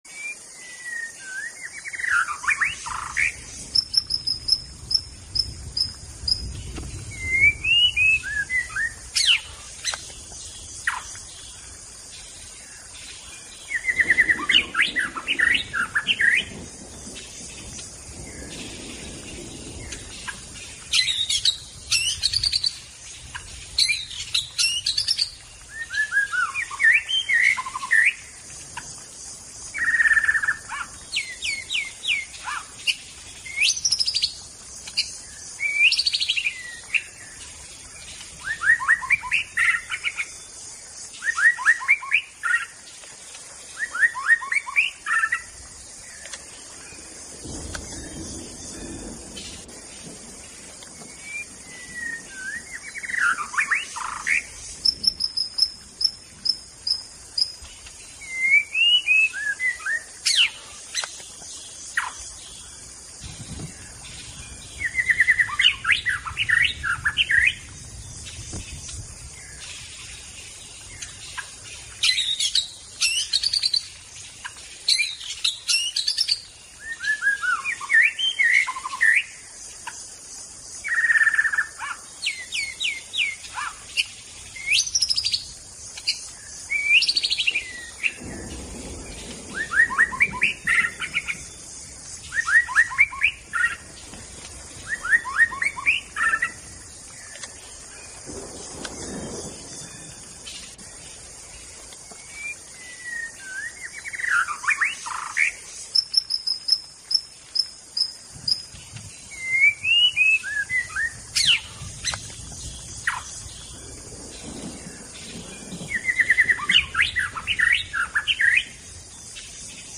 Tiếng Chích Chòe Lửa hót
Tiếng động vật 548 lượt xem 23/02/2026
Với giọng hót lắt léo, nhiều tầng âm thanh và khả năng luyến láy cực kỳ điêu luyện, Chích Chòe Lửa luôn chiếm trọn trái tim của những người yêu chim cảnh. File âm thanh này ghi lại những tràng hót dài, có độ vang xa và sự biến hóa khôn lường, rất phù hợp để làm tư liệu luyện chim hoặc lồng ghép vào các sản phẩm truyền thông về thiên nhiên.
• Giai điệu lôi cuốn và phức tạp: Khác với nhiều loài chim khác, Chích Chòe Lửa có giọng hót mang tính nghệ thuật cao với nhiều đoạn "đảo giọng" bất ngờ.
• Xử lý kỹ thuật sạch sẽ: Bản thu đã được loại bỏ tạp âm nhiễu, tiếng gió và các âm thanh không mong muốn, đảm bảo giữ được độ sắc nét của từng nốt nhạc ngay cả khi phát ở âm lượng lớn.